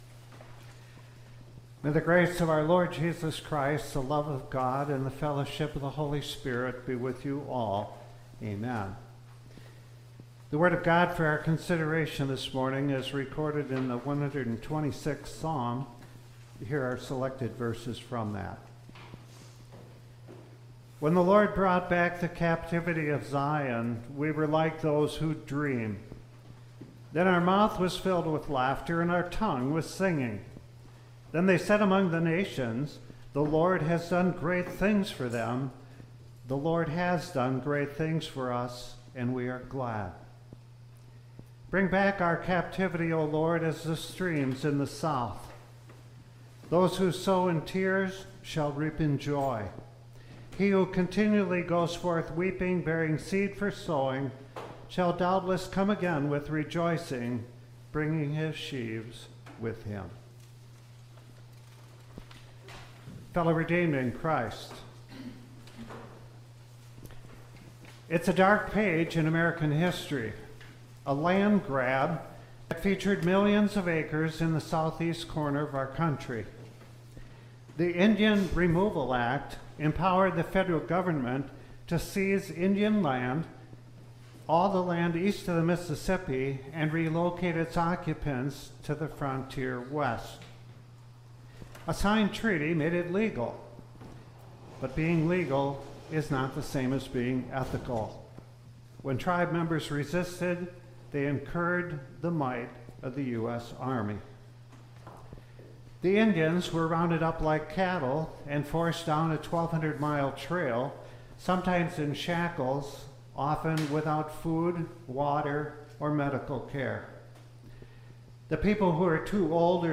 All-Saints-Day-Sunday-Service-November-2-2025.mp3